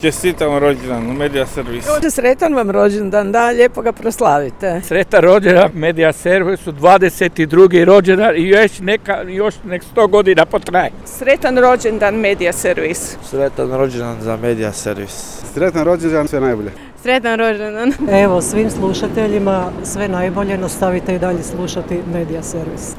Prigodne rođendanske čestitke svima koji svakodnevno sudjeluju u stvaranju informativnog sadržaja, uputili su i brojni građani.